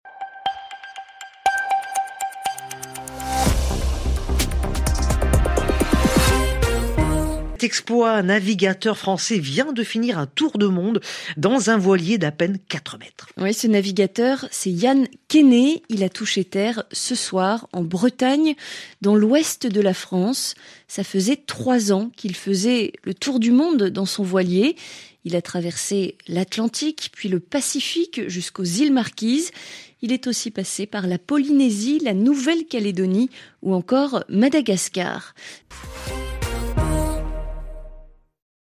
Extrait du Journal en français facile du 02/08/2022 (RFI)